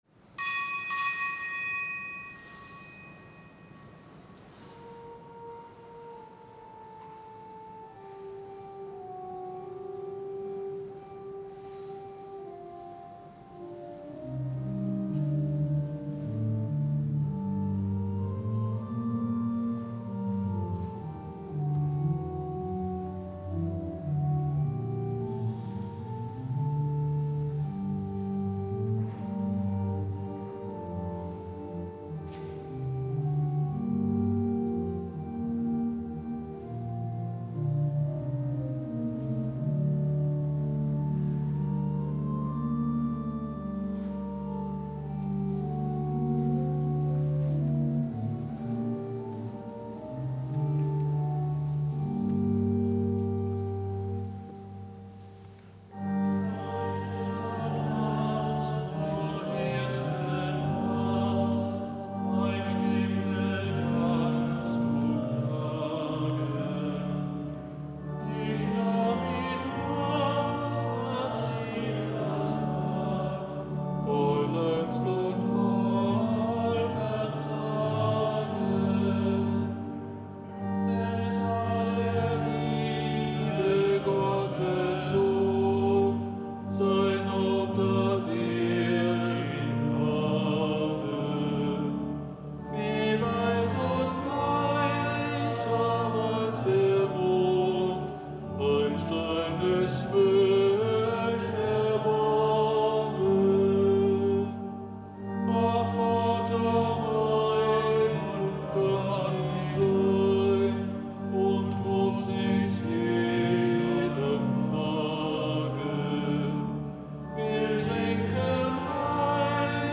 Kapitelsmesse aus dem Kölner Dom am Montag der Karwoche. Zelebrant: Dompropst Guido Assmann.